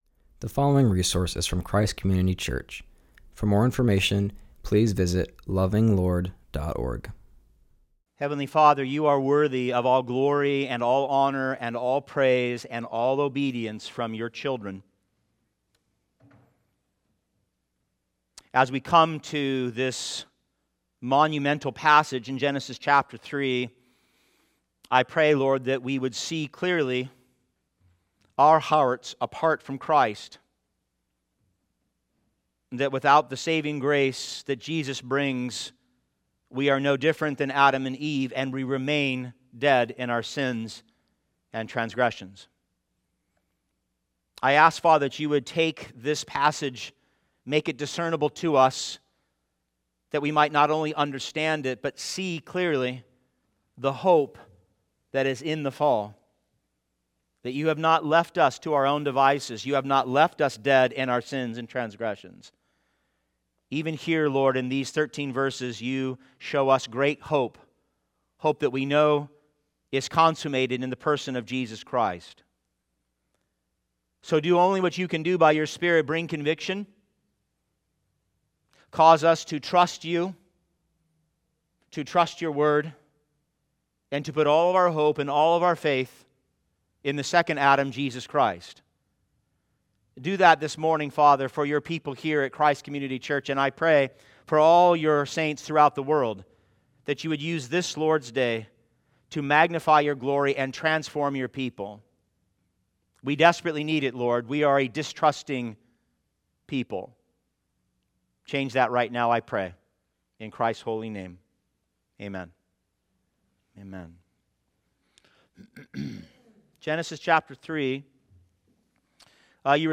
continues our series and preaches from Genesis 3:1-13.